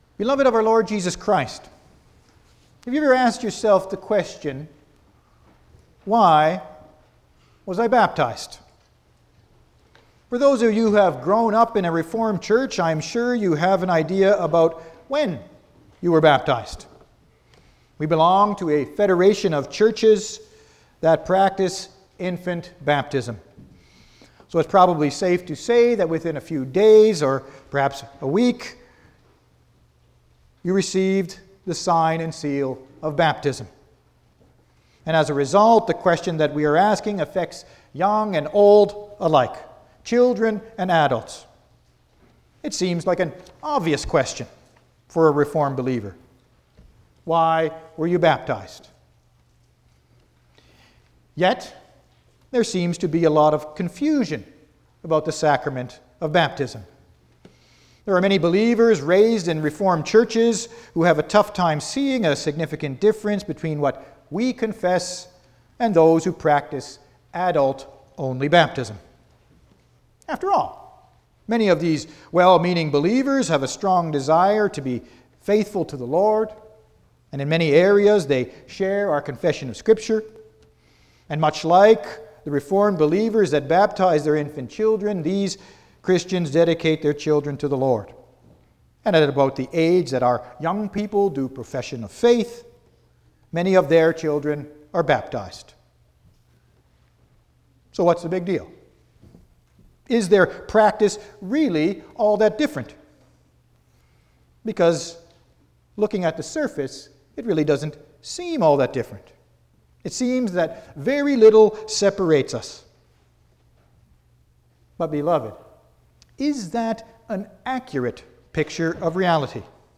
09-Sermon.mp3